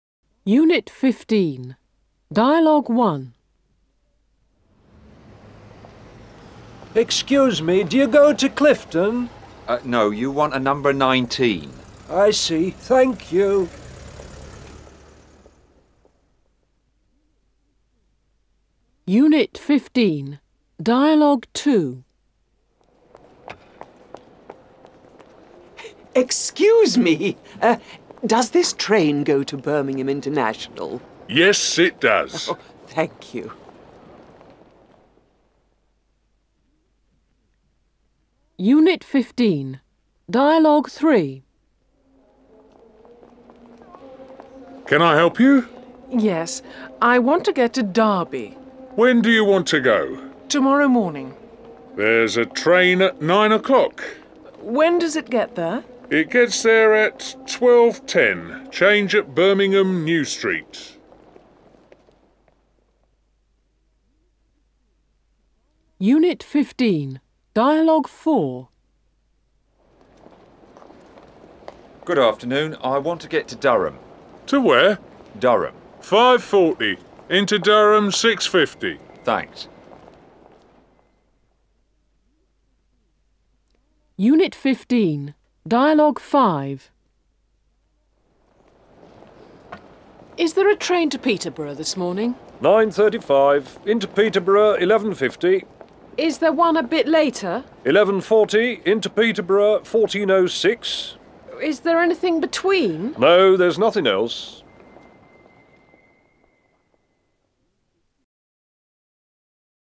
09 - Unit 15, Dialogues.mp3